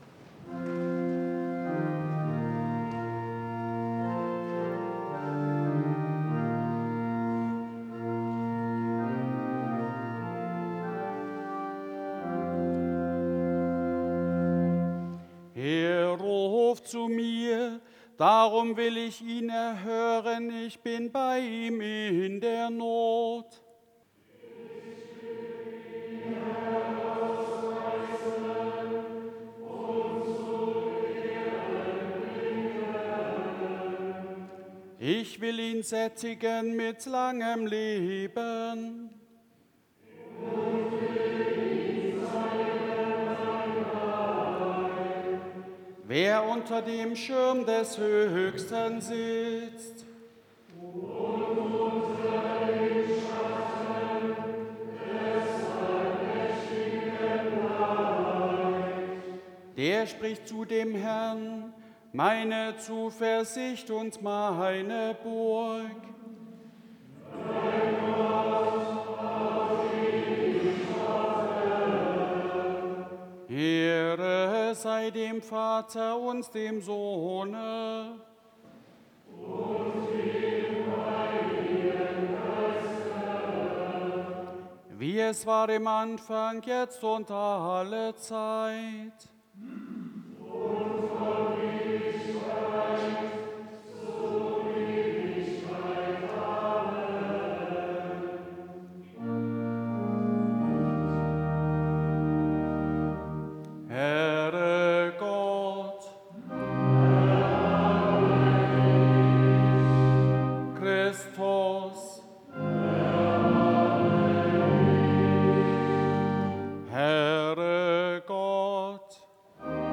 Eingangsliturgie Ev.-Luth.
Audiomitschnitt unseres Gottesdienstes am Sonntag Invokavit 2025.